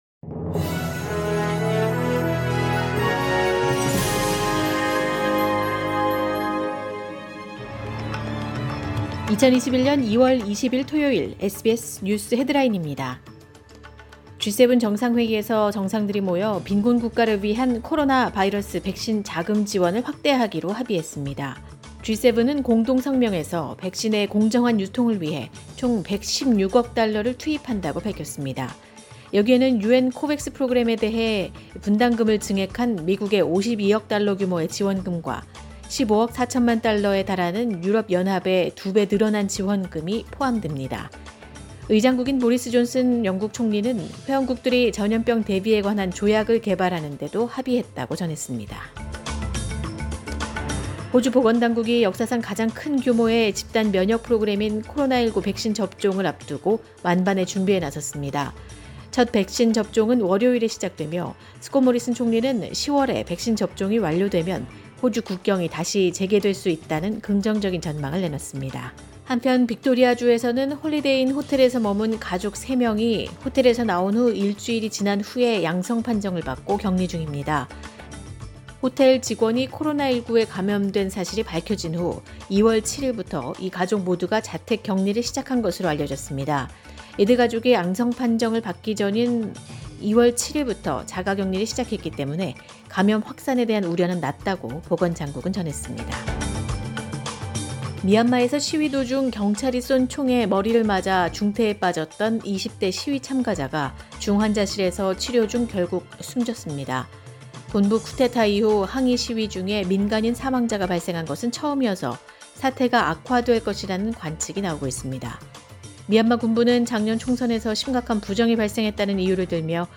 2021년 2월 20일 토요일 SBS 뉴스 헤드라인입니다.